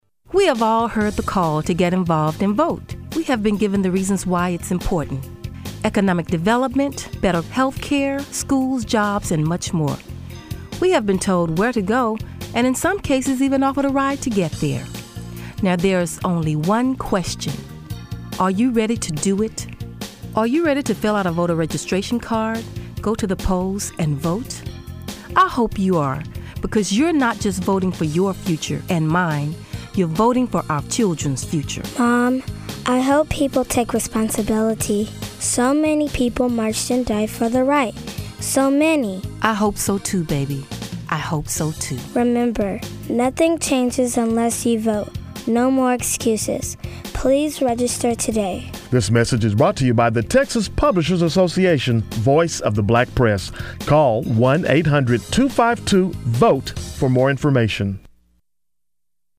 Radio Spot #2,